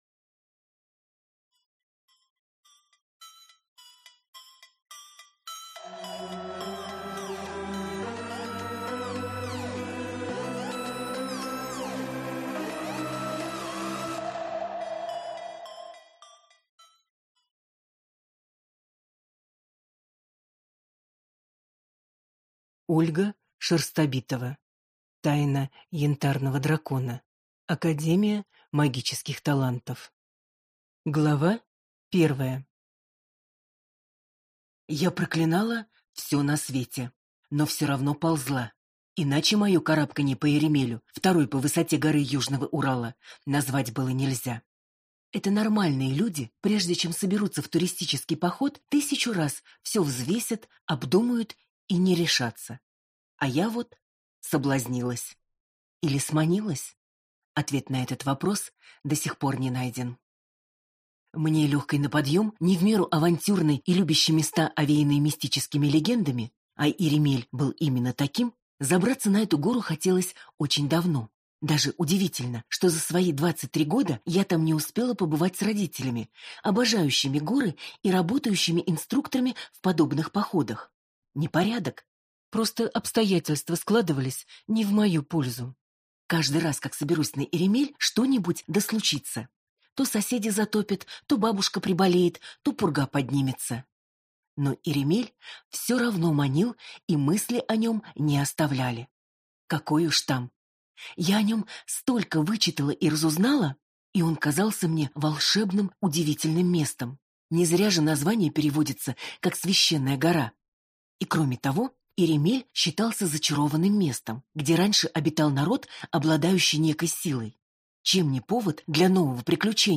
Аудиокнига Академия Магических Талантов. Тайна янтарного дракона | Библиотека аудиокниг